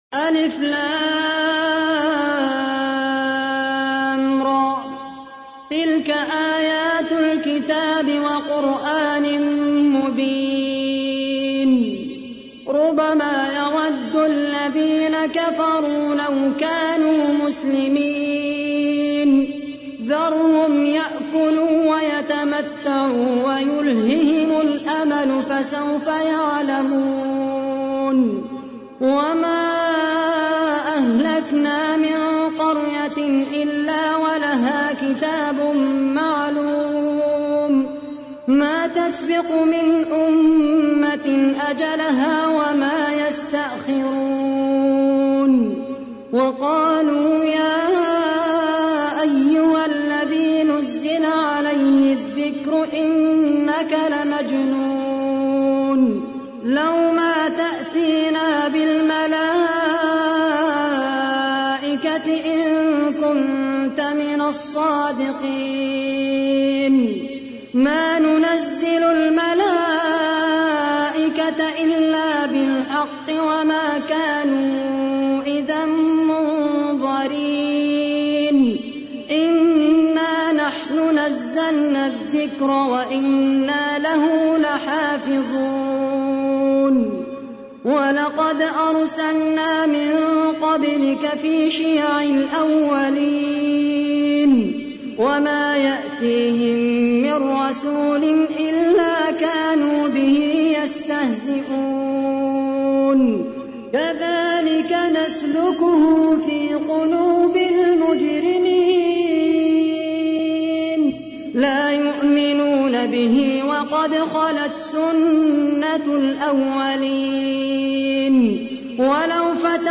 تحميل : 15. سورة الحجر / القارئ خالد القحطاني / القرآن الكريم / موقع يا حسين